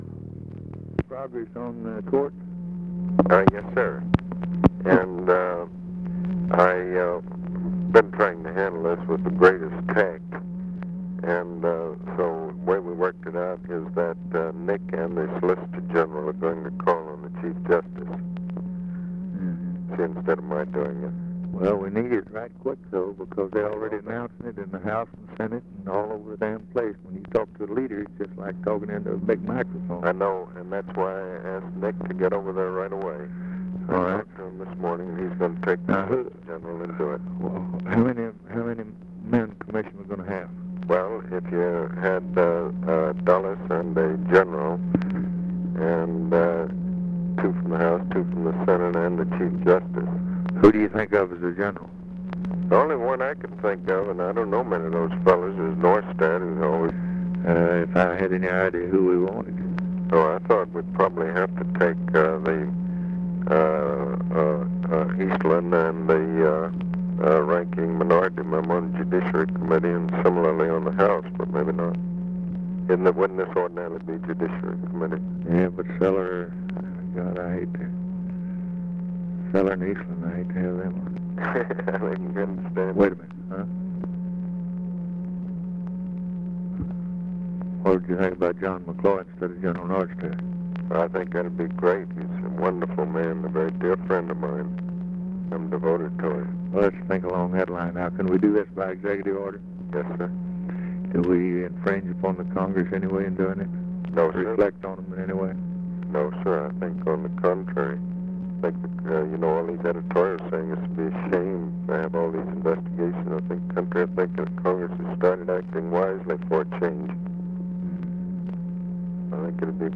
Conversation with ABE FORTAS, November 29, 1963
Secret White House Tapes